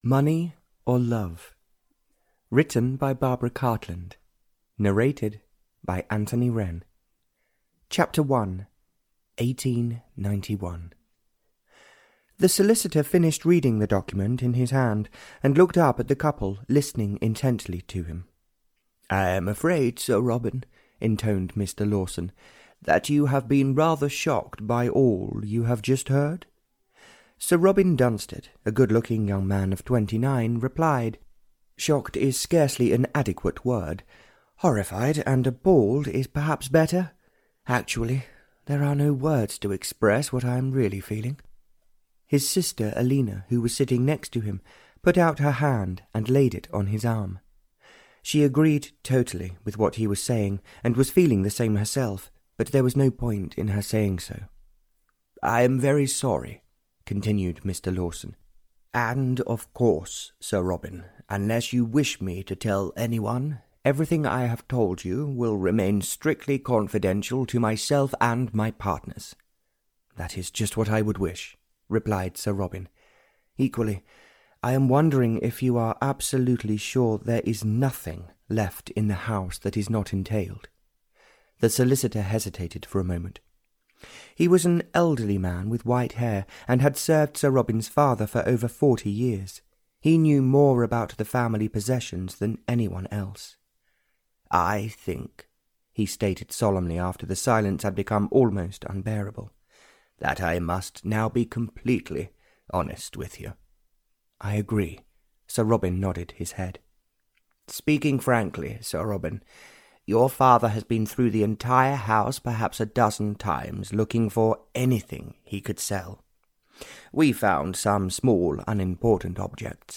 Audio knihaMoney or Love (Barbara Cartland s Pink Collection 72) (EN)
Ukázka z knihy